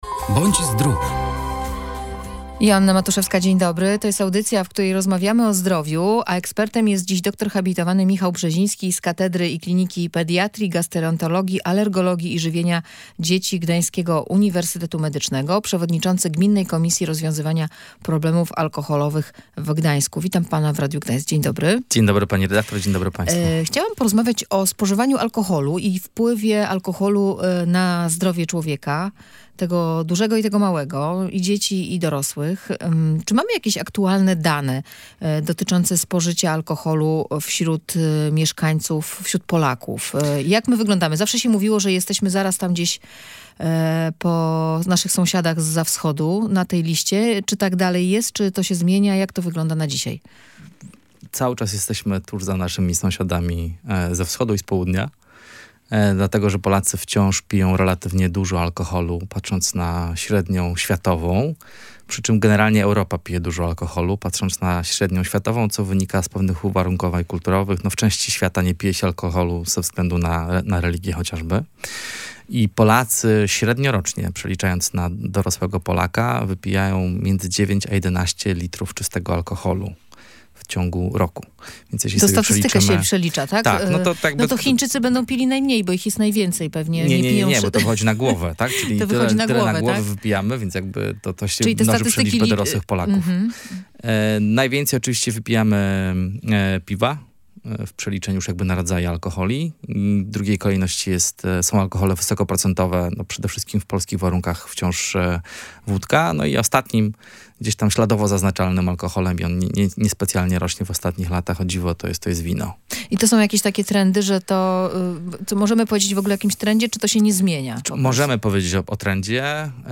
Między innymi o trendach związanych ze spożyciem alkoholu i innych substancji psychoaktywnych rozmawialiśmy w audycji "Bądź zdrów".